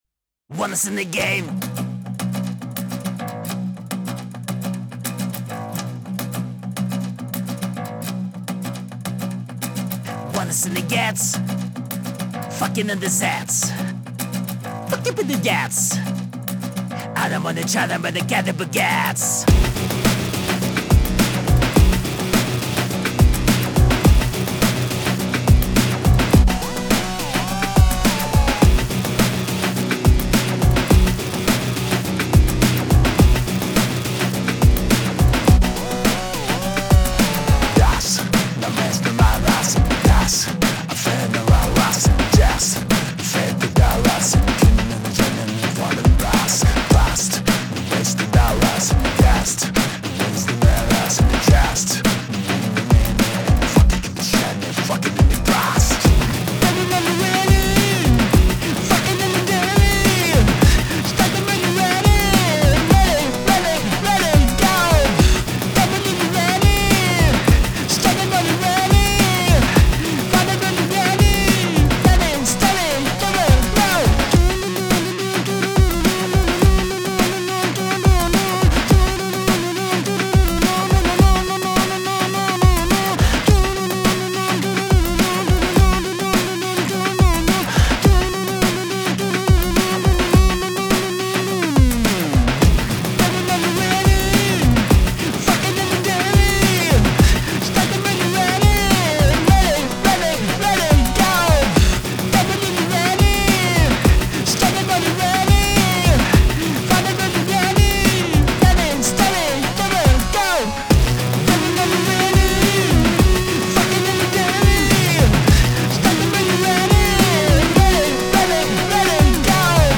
ELECTRO ROCK (LIMP BIZKIT STYLE)
mad-rock-demo-mp3-b4b18ef8